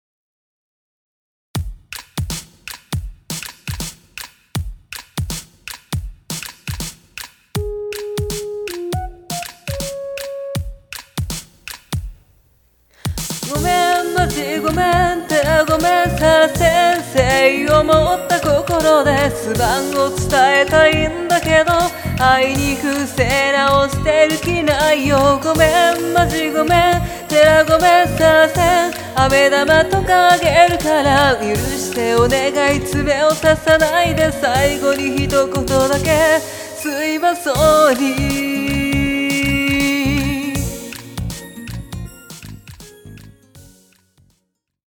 ♪---普通